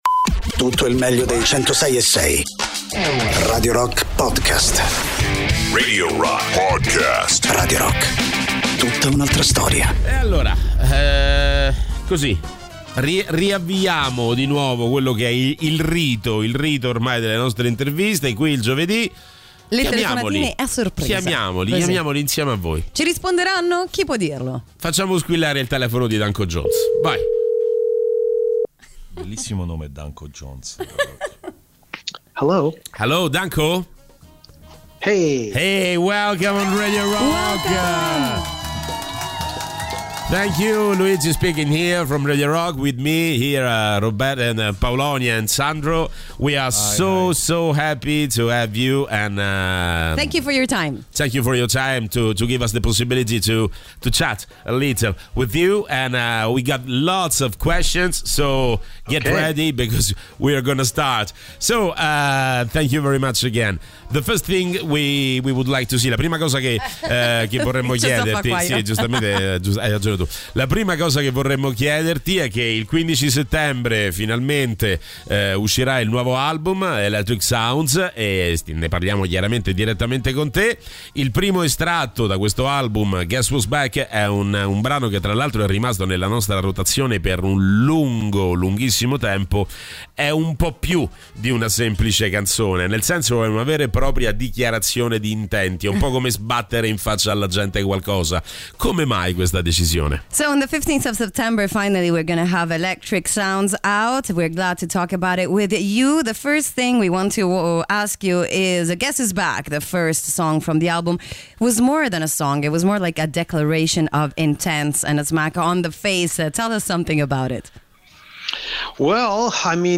Interviste: Danko Jones (20-07-23)